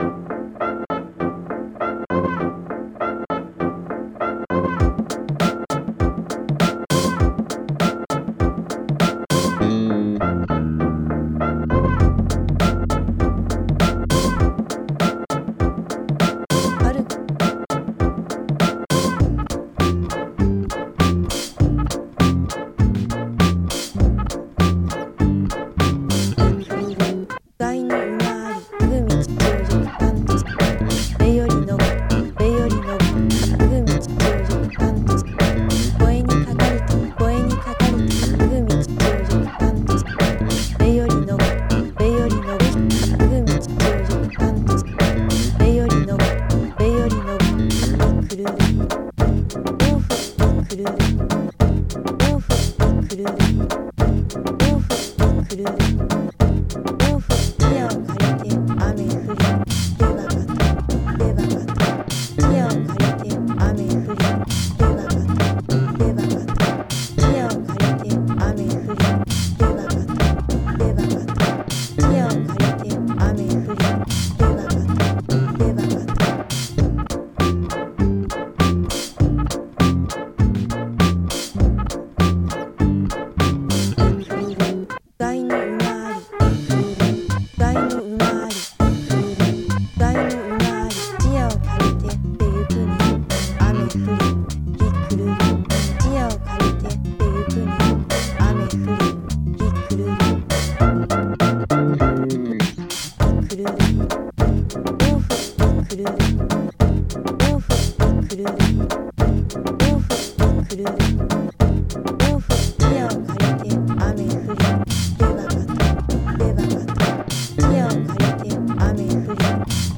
was sampled and played over an acoustic song